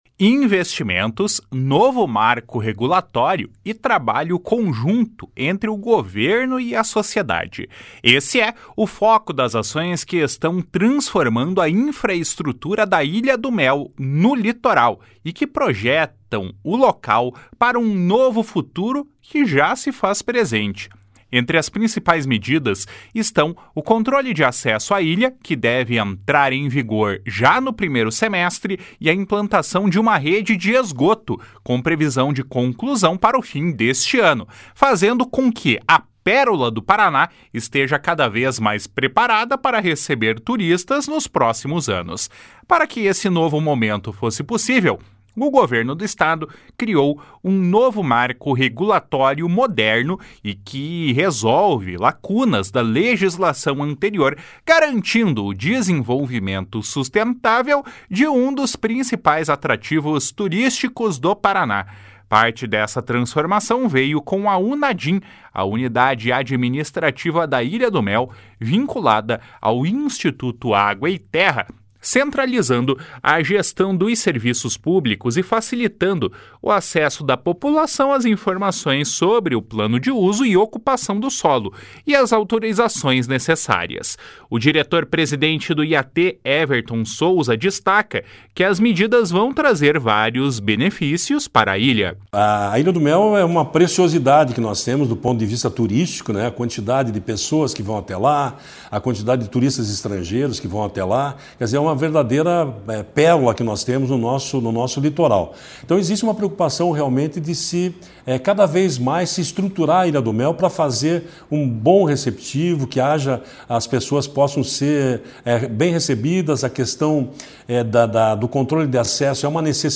O diretor-presidente do IAT, Everton Souza, destaca que as medidas vão trazer vários benefícios para a ilha.